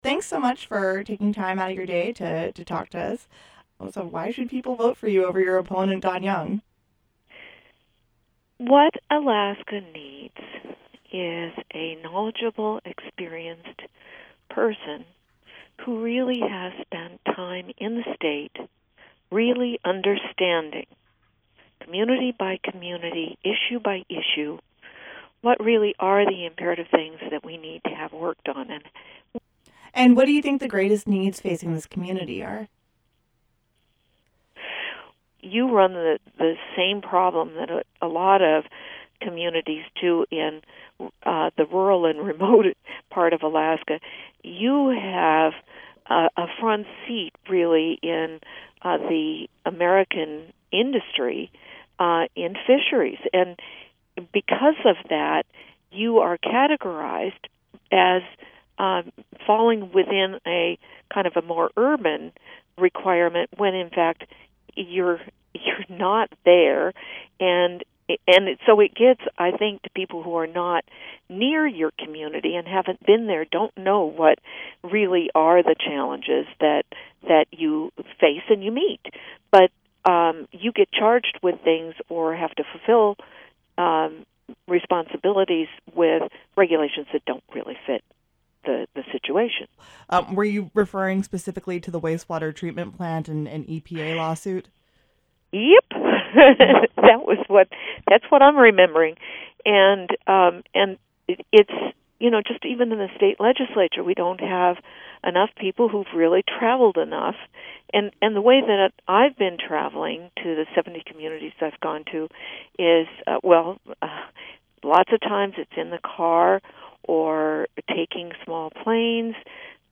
This week, KUCB is airing interviews with candidates for Alaska’s one congressional seat and with the region’s representatives in the State Legislature who are up for reelection.